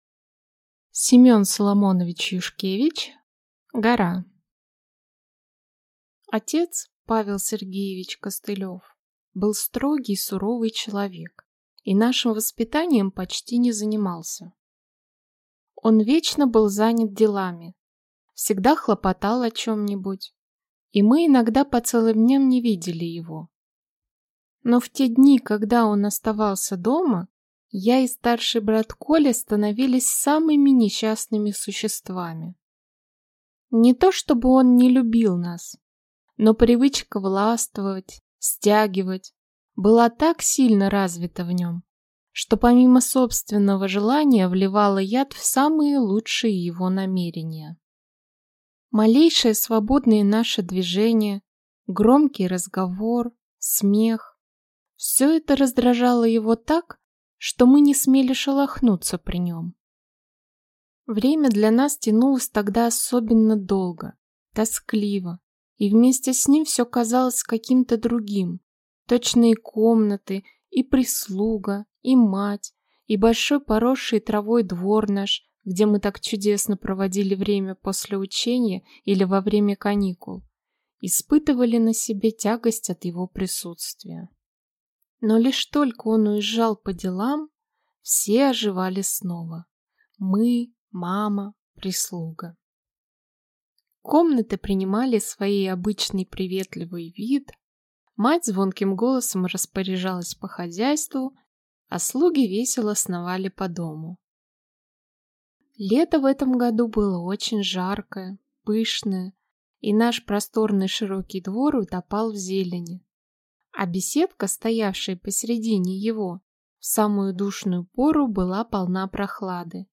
Аудиокнига Гора | Библиотека аудиокниг